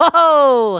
01_luigi_hoohoo.aiff